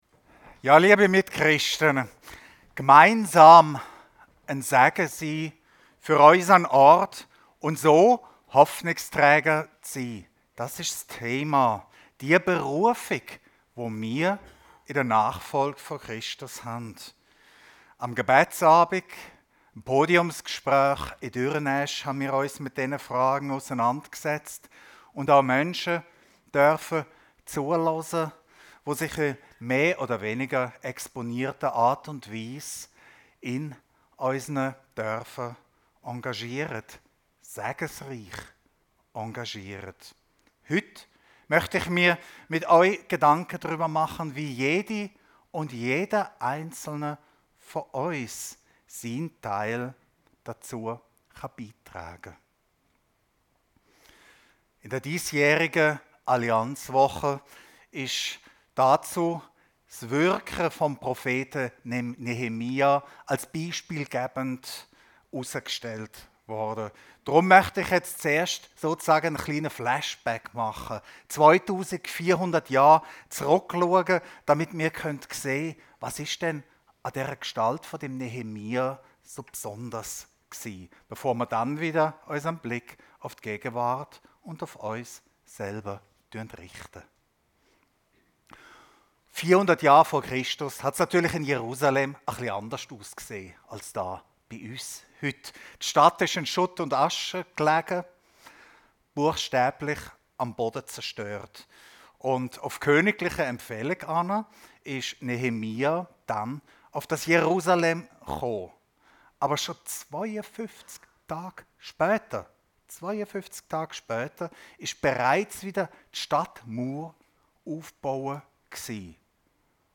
Gemeinsam ein Segen für unser Umfeld – seetal chile Predigten